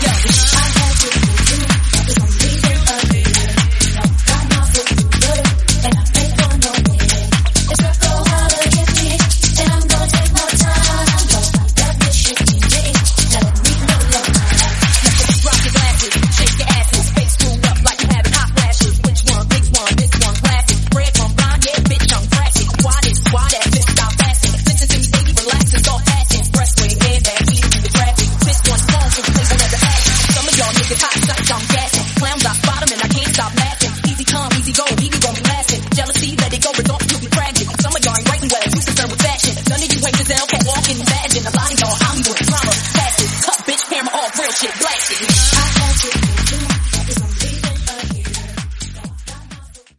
Genres: EDM , RE-DRUM , TOP40
Clean BPM: 140 Time